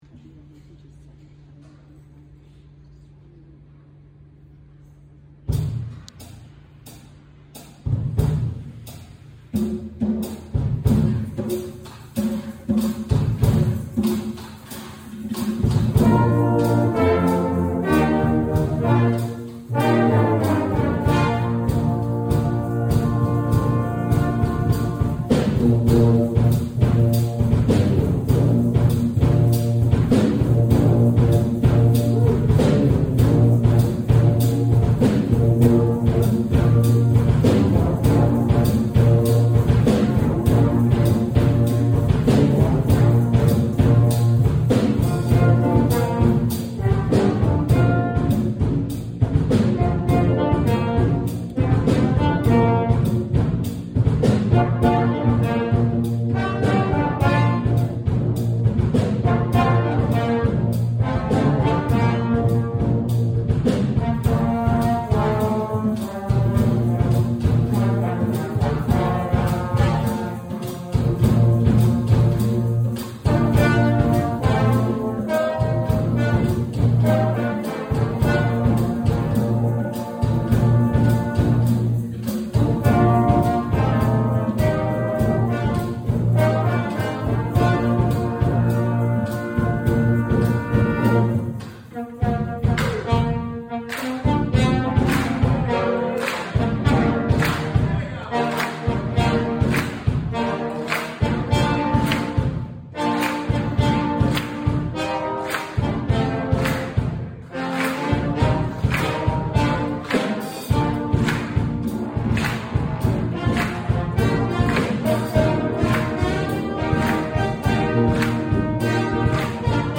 Hlonolofatsa | Low Brass Feature